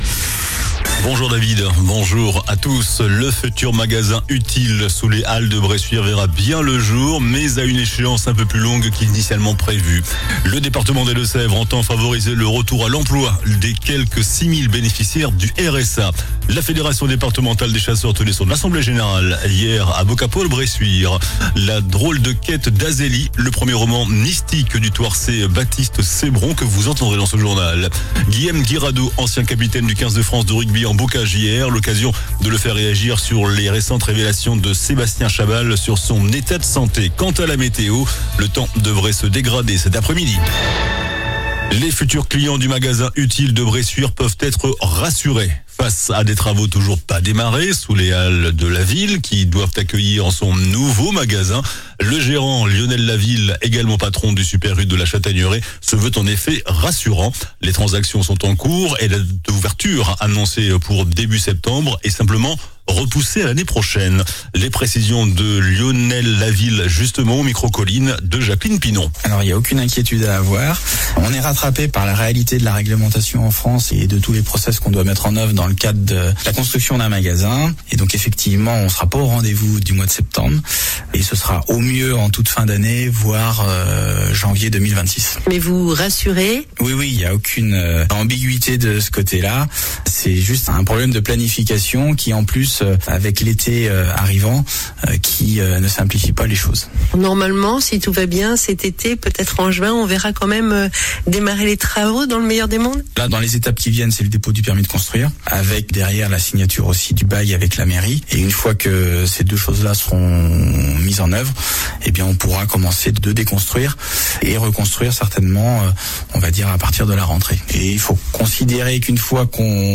JOURNAL DU VENDREDI 18 AVRIL ( MIDI )